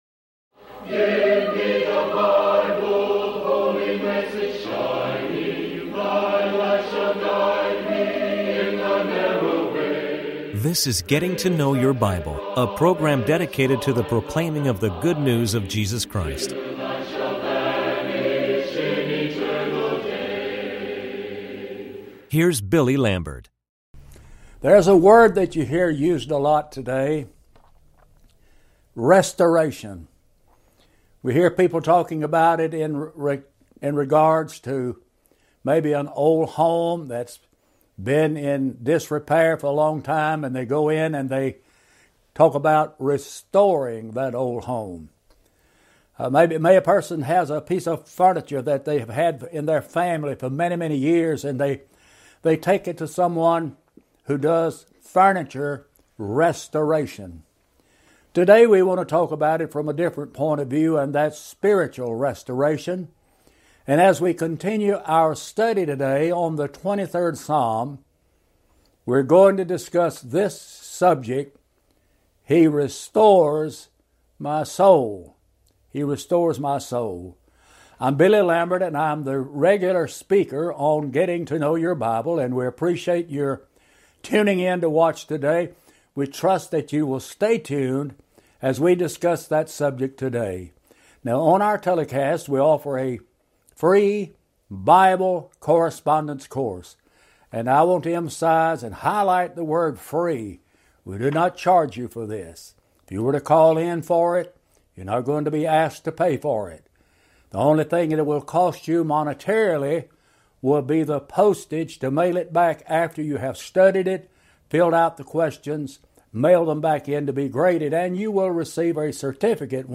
Talk Show Episode, Audio Podcast, Getting To Know Your Bible and Ep1503, He Restores My Soul on , show guests , about He Restores My Soul, categorized as History,Love & Relationships,Philosophy,Psychology,Religion,Christianity,Inspirational,Motivational,Society and Culture